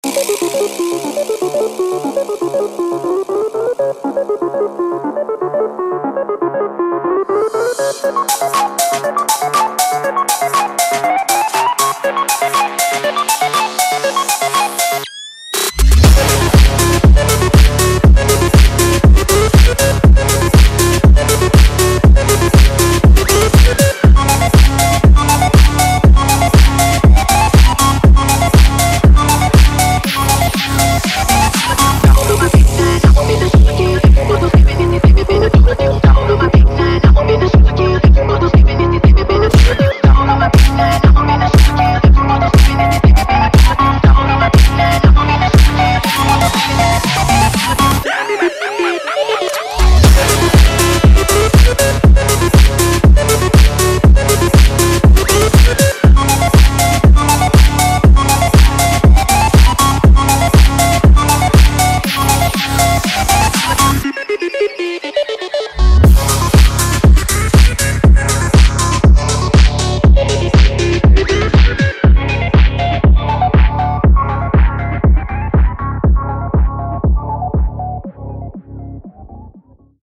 PACMAN-PHONK.mp3